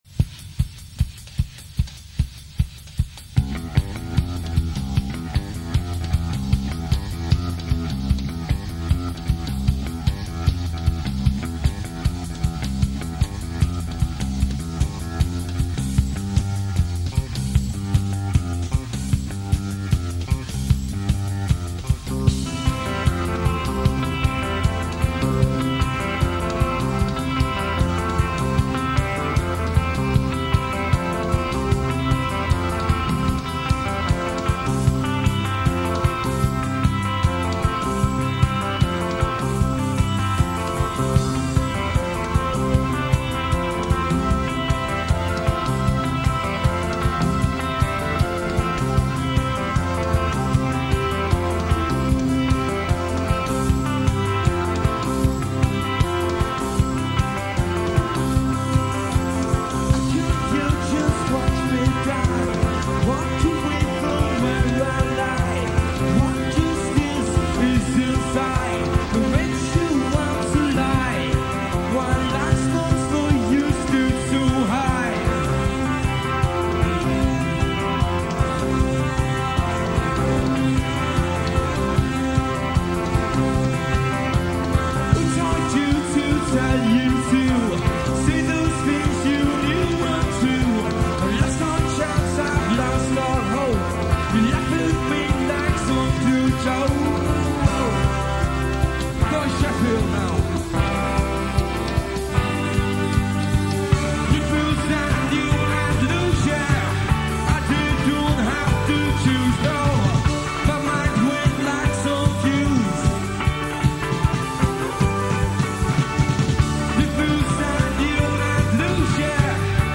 Forgotten bands of the Madchester Years.
live at Sheffield Poly